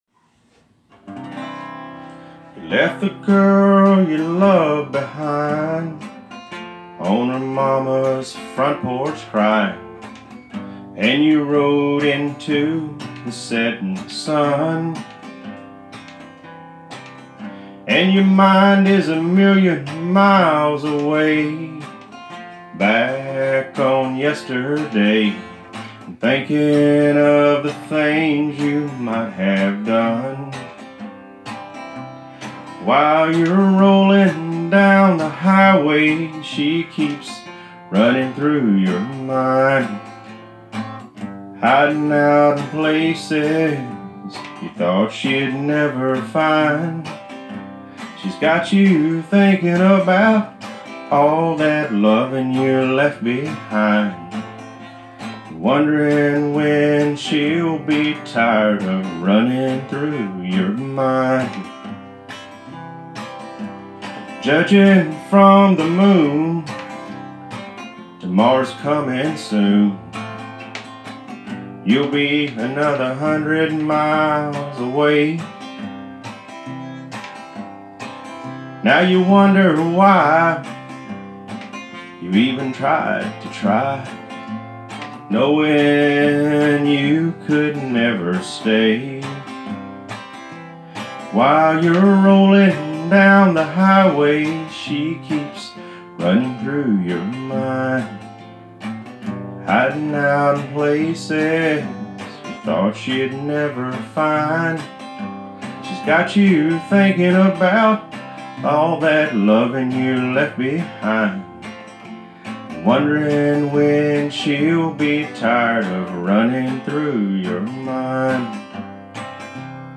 Admittedly very unpolished.